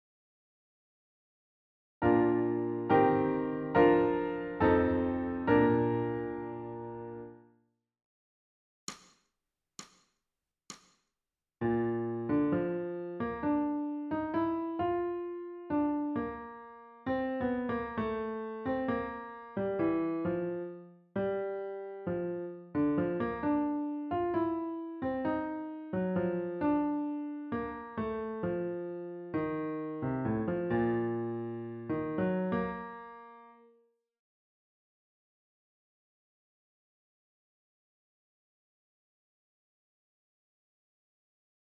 ソルフェージュ 聴音: 2-1-17